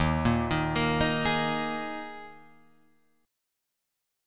D#sus4 chord